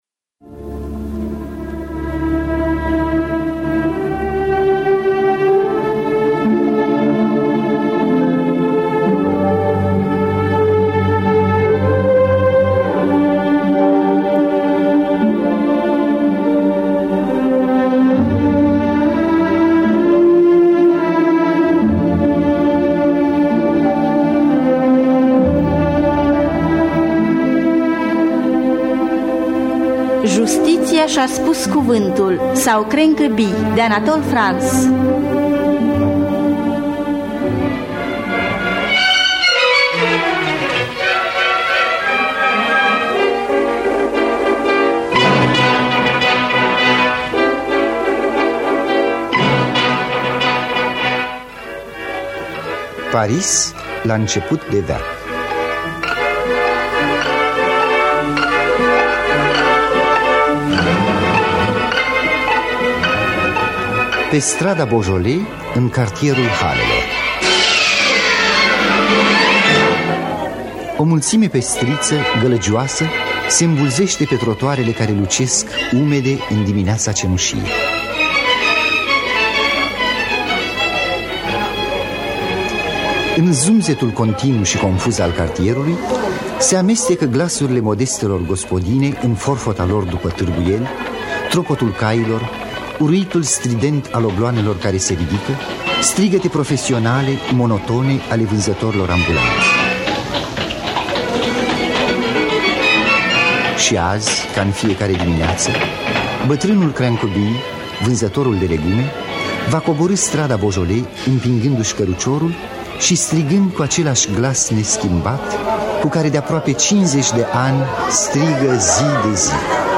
Înregistrare din anul 1955 (30 ianuarie).